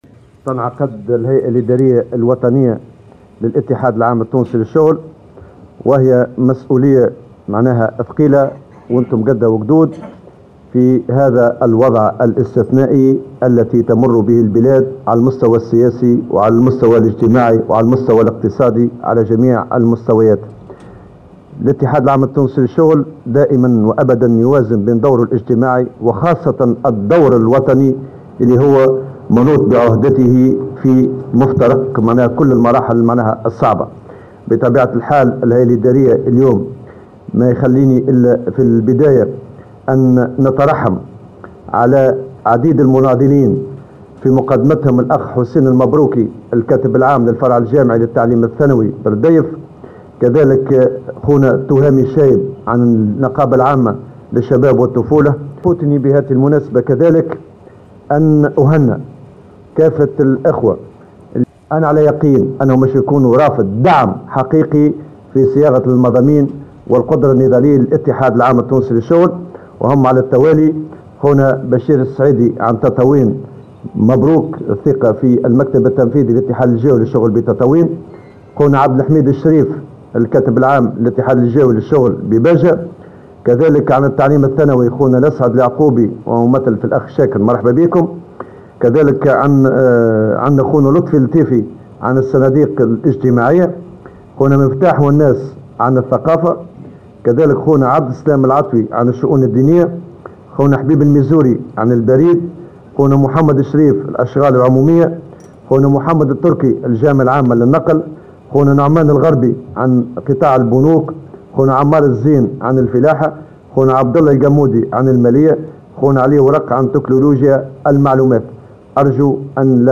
وفي كلمة الافتتاح قال الأمين العام للإتحاد نور الدين الطبوبي إن الهيئة الادارية تنعقد في وضع استثنائي على المستوى السياسي والاجتماعي والاقتصادي . وسيتناول المؤتمرون ثلاث قضايا أساسية تتمثل في المفاوضات الاجتماعية والمسألة السياسية والوضع الاقتصادي .